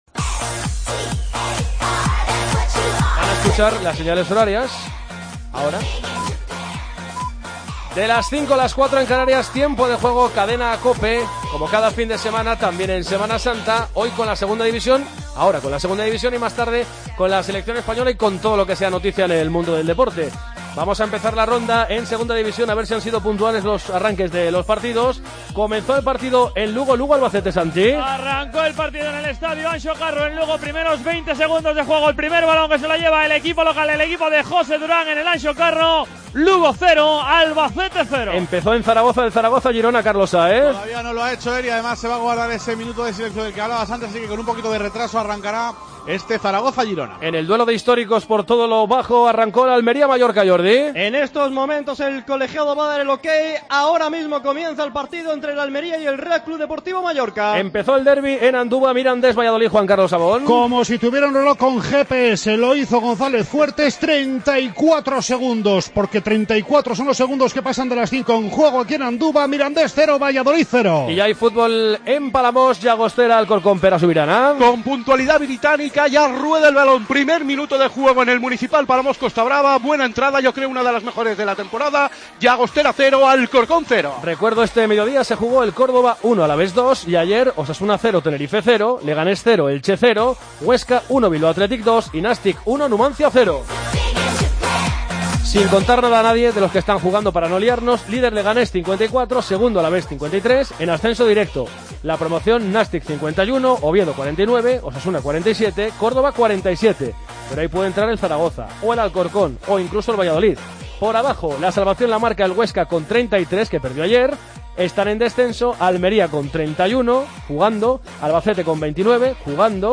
Escucha la primera parte de los encuentros de la 31ª jornada de la Liga Adelante: Mirandés - Valladolid; Almería - Mallorca; Llagostera - Alcorcón; Lugo - Albacete; Zaragoza - Girona. Además, todo el polideportivo en directo.